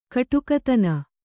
♪ kaṭukatana